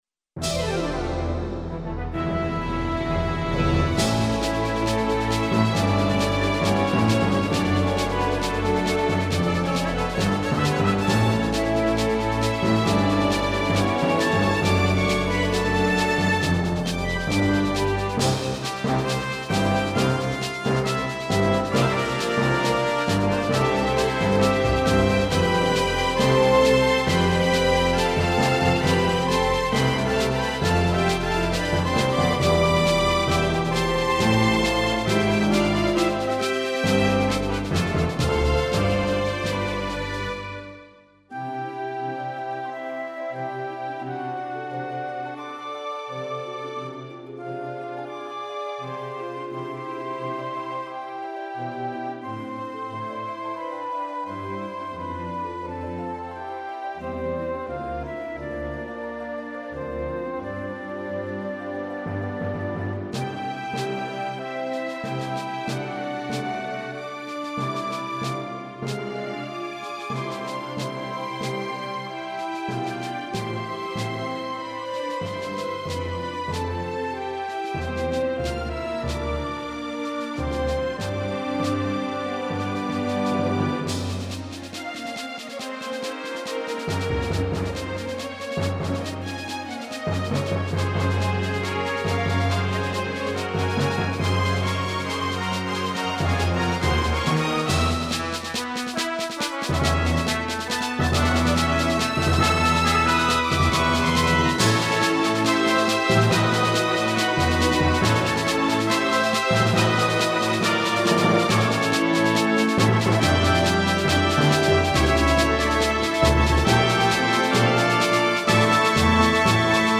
エレクトーンでゲーム音楽を弾きちらすコーナー。
使用機種：ELS-01C